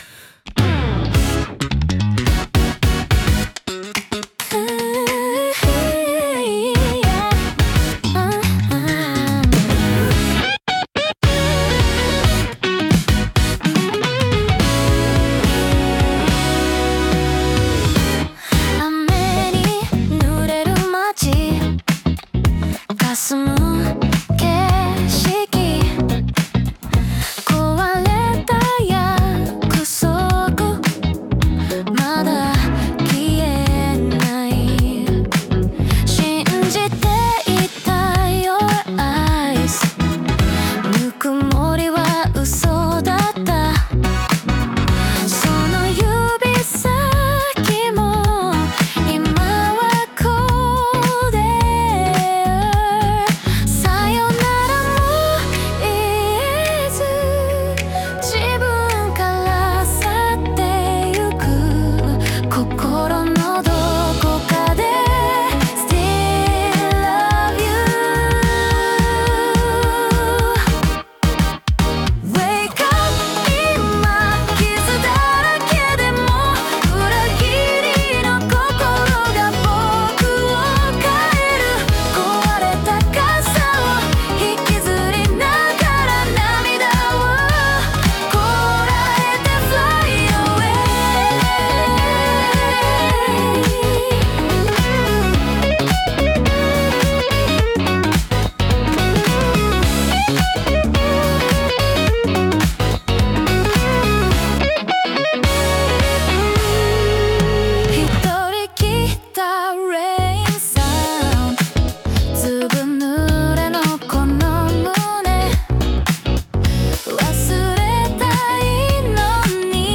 イメージ：J-POP,女性ボーカル,８０年代,シティーポップ